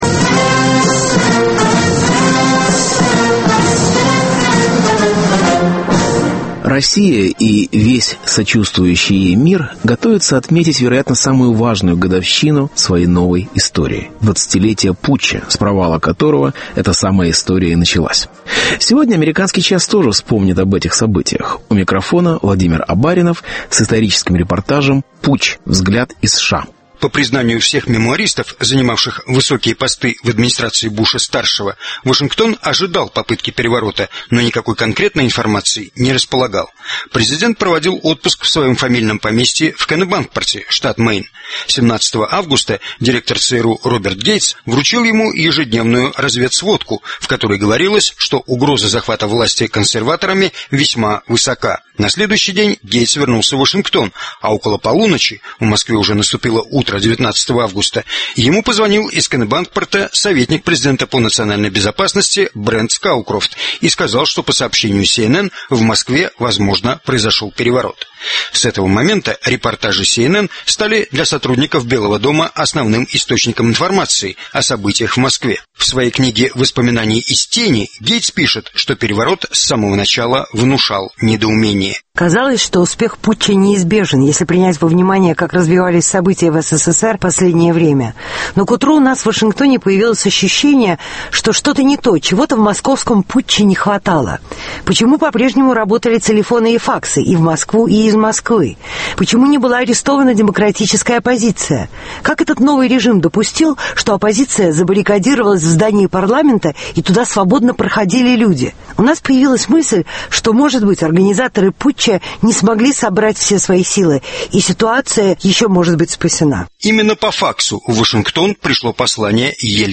Путч. Взгляд из Америки. Исторический репортаж